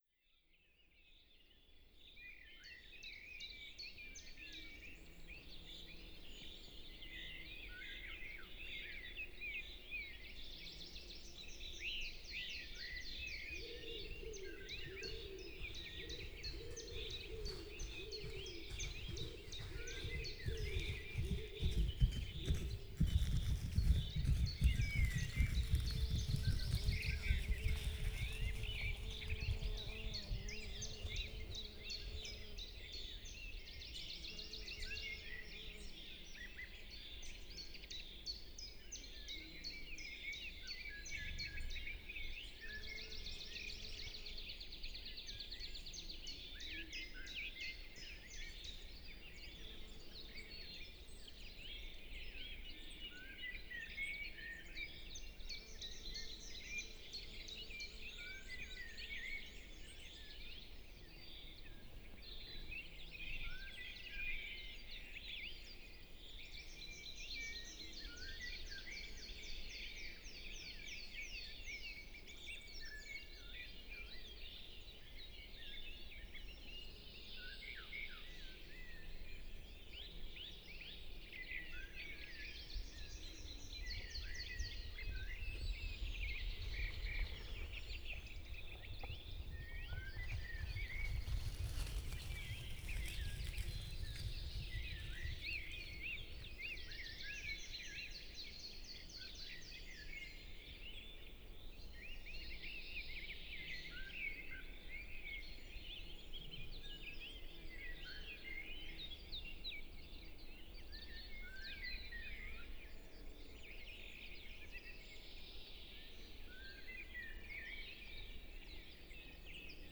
tu je nahrávka. Odporúčam vypočuť si ju cez slúchadlá, ten priestorový spev vtákov, ale aj príchod návštevníka za to stojí.
srnec_z_bisaru.flac